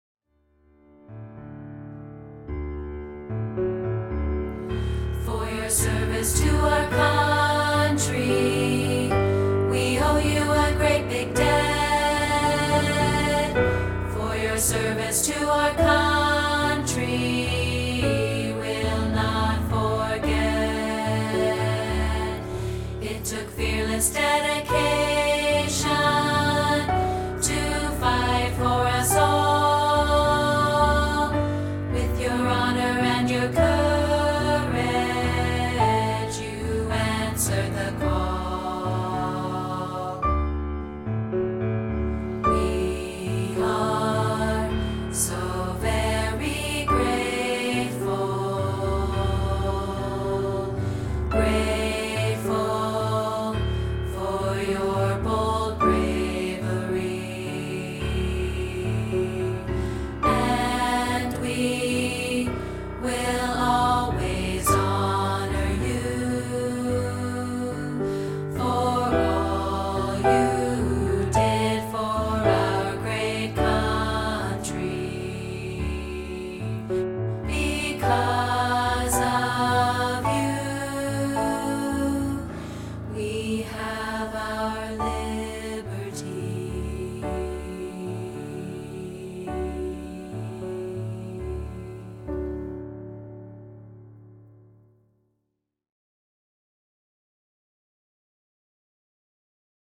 This is a rehearsal track of part 3, isolated.